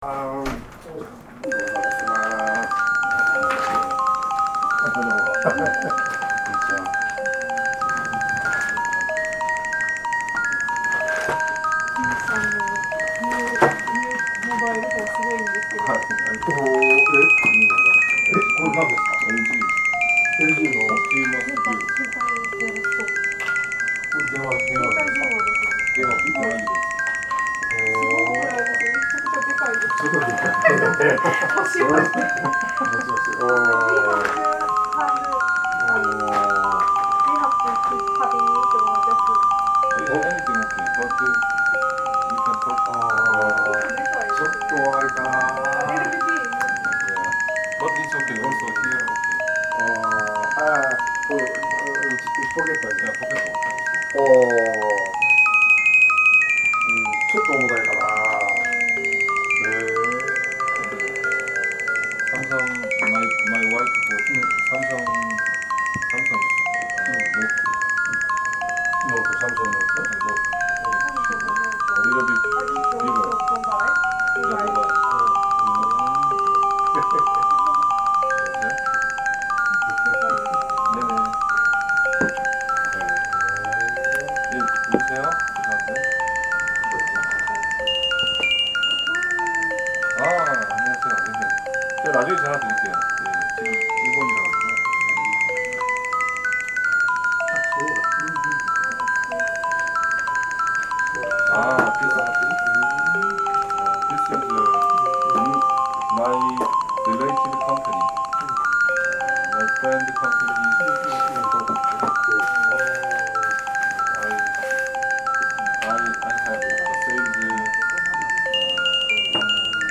電子オルゴール
私が作ったオルゴール